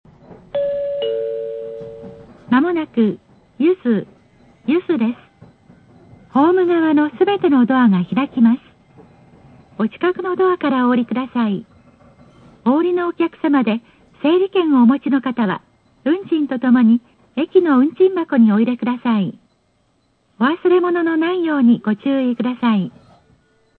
柚須到着前の放送。ドアの開閉案内など標準的な放送となる。